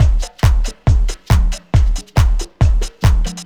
FullLoop20-44S.wav